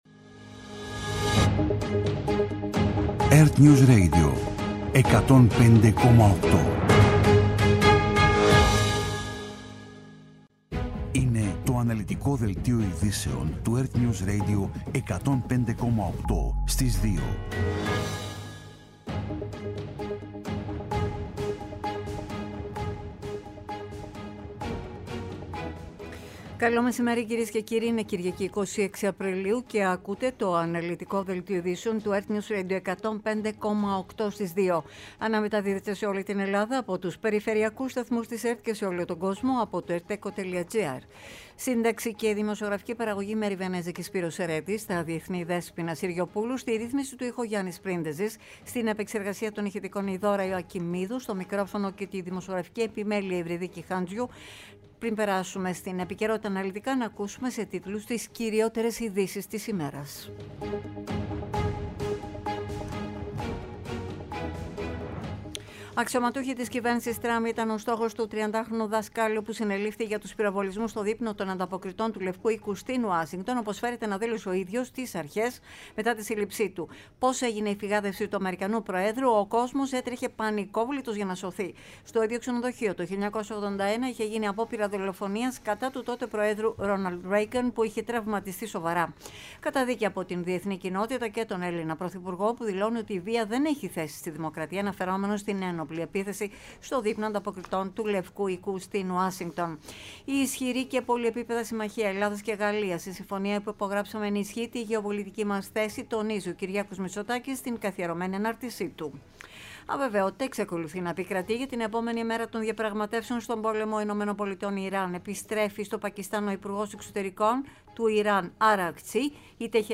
Το κεντρικό ενημερωτικό μαγκαζίνο στις 14.00.
Με το μεγαλύτερο δίκτυο ανταποκριτών σε όλη τη χώρα, αναλυτικά ρεπορτάζ και συνεντεύξεις επικαιρότητας.